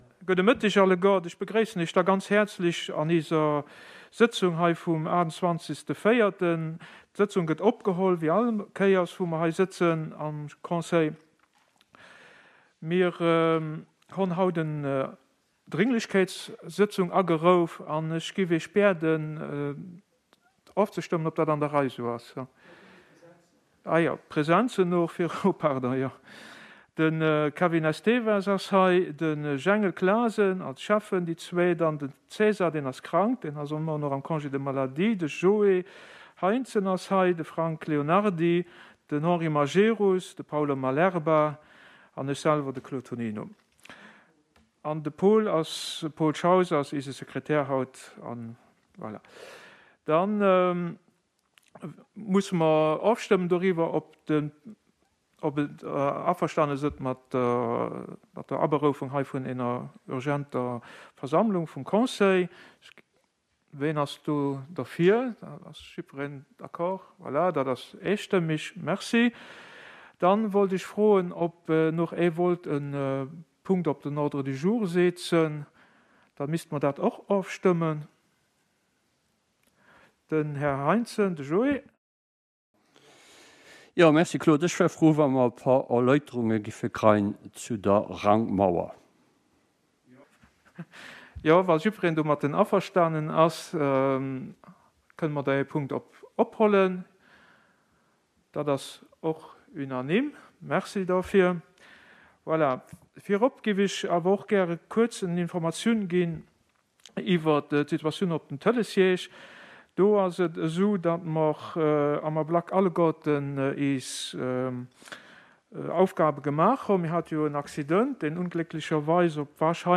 Conseil Communal du mercredi, 28 avril 2021 à 16.30 heures en la salle Bessling du Centre Culturel Larei
Présents: TONINO Claude, bourgmestre –  ESTEVES Kevin, KLASEN Jengel, échevins –  MAJERUS Henri, HEINTZEN Joe, MALERBA Paolo, LEONARDY Frank conseillers –
Questions des conseillers LEONARDY Frank, HEINTZEN Joe